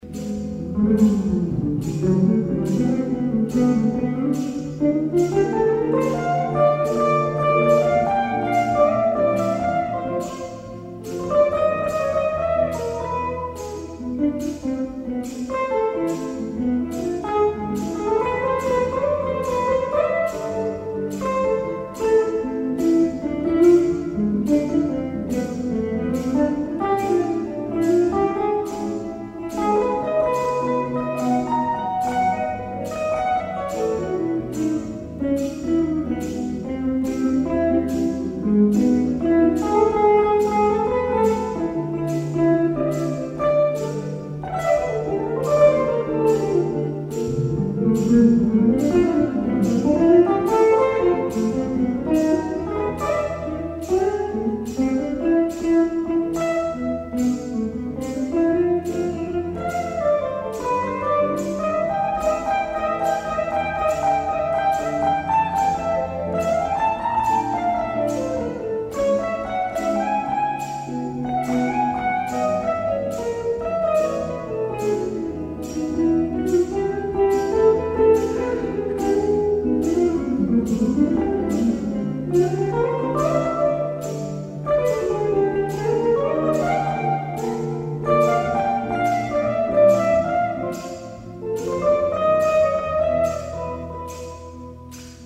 ディスク１－２：ライブ・アット・すみだトリフォニーホール、東京 06/11/2010
※試聴用に実際より音質を落としています。